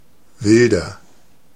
Ääntäminen
Ääntäminen Tuntematon aksentti: IPA: /ˈvɪl.dɐ/ Haettu sana löytyi näillä lähdekielillä: saksa Käännöksiä ei löytynyt valitulle kohdekielelle. Wilder on sanan wild komparatiivi.